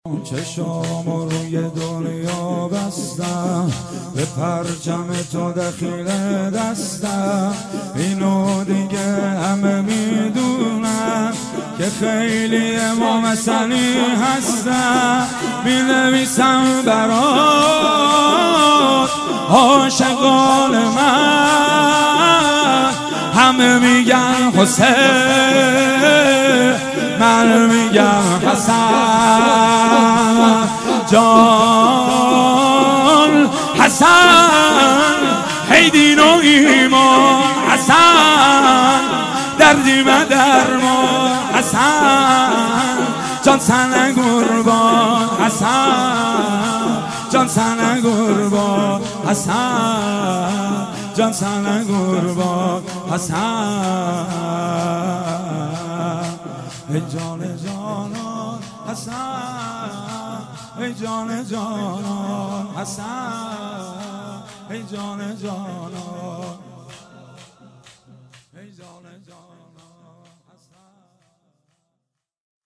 روضه انصارالزهرا سلام الله علیها
چشمامو روی دنیا _ شور
اقامه عزای روضه حضرت صدیقه شهیده علیها السلام _ شب دوم